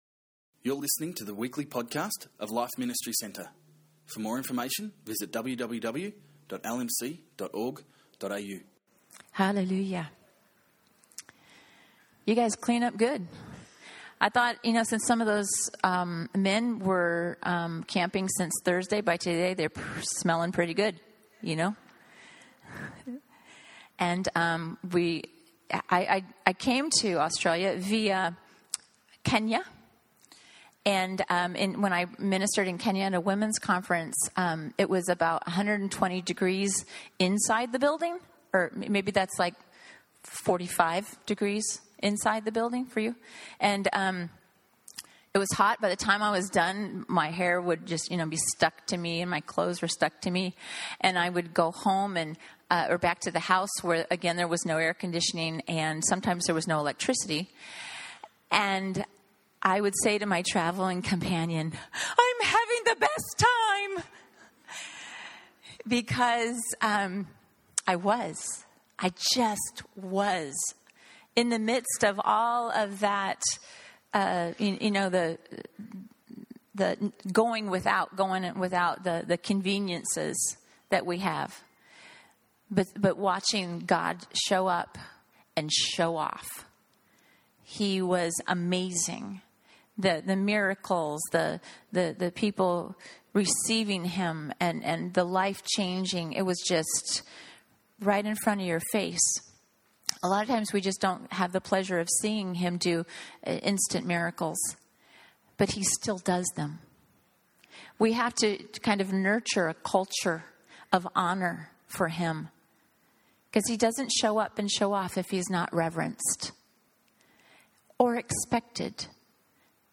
Testimony of Healing